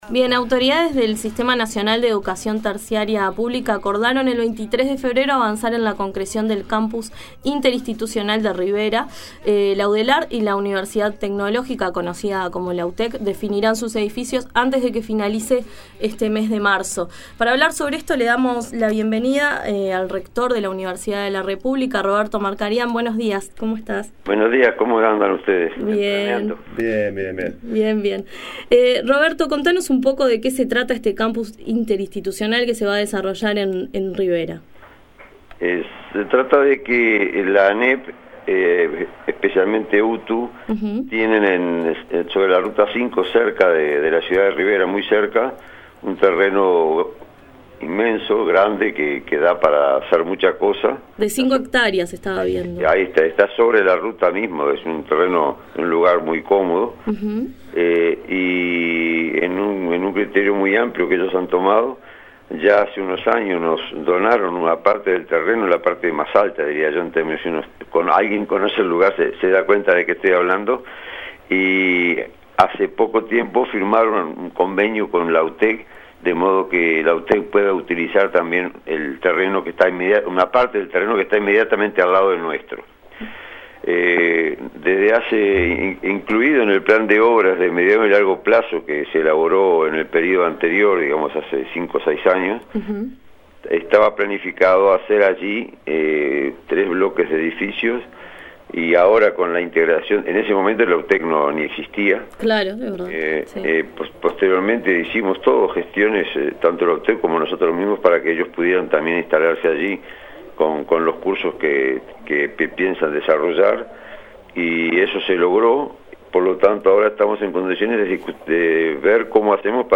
La mañana de Uni Radio dialogó con el rector de la Universidad de la República, Roberto Markarián, sobre la creación de un campus interinstitucional que se instalará en el departamento de Rivera.